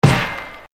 Hit 011.wav